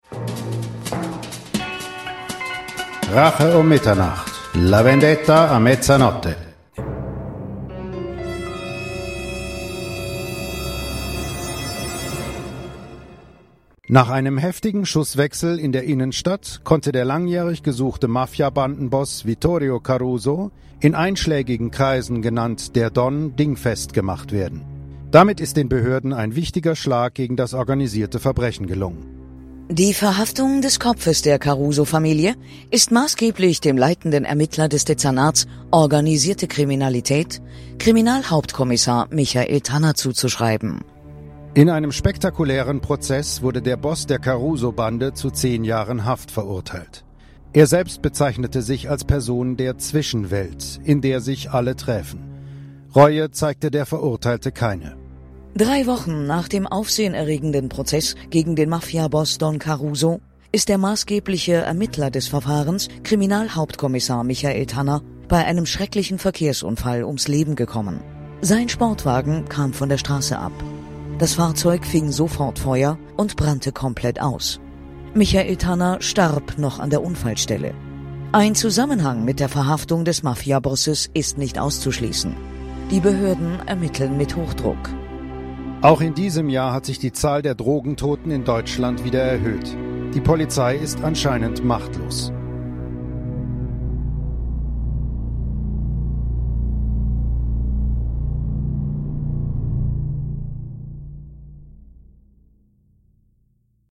RUM-Radioansage.mp3